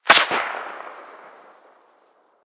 Incendiary_Far_04.ogg